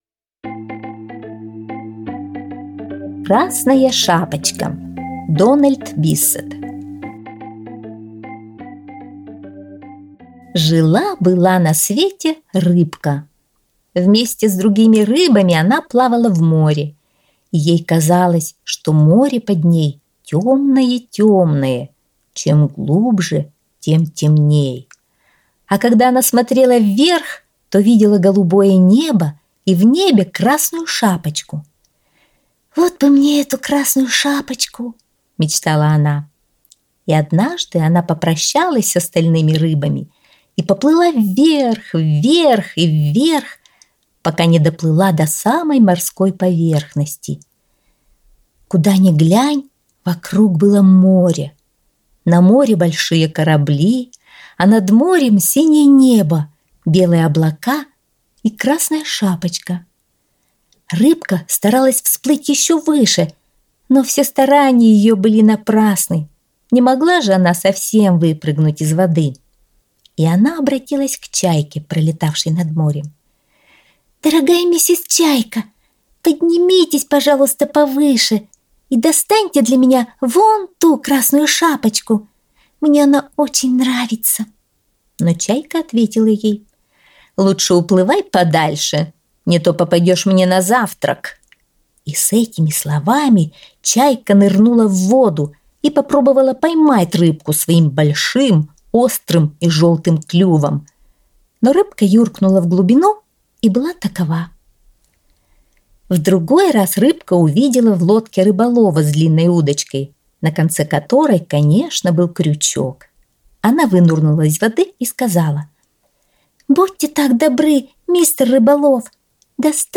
Аудиосказка «Красная шапочка»